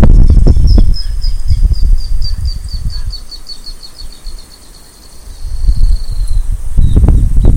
Short-billed Canastero (Asthenes baeri)
Country: Argentina
Province / Department: Entre Ríos
Condition: Wild
Certainty: Recorded vocal